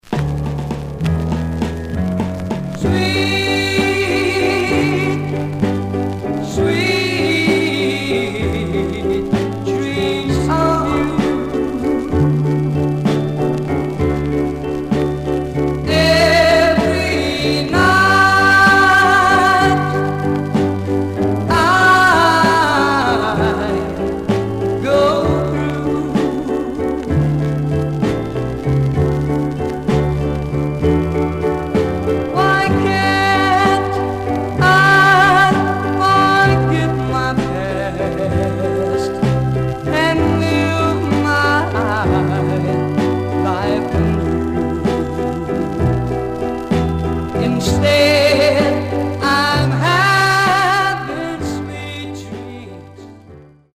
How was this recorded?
Condition: M- SLIGHT WARP Condition Surface noise/wear Stereo/mono Mono